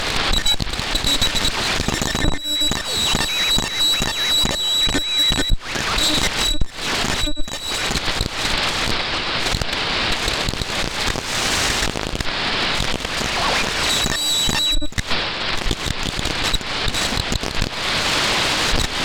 MFJ-269C Antenna SWR Analyzer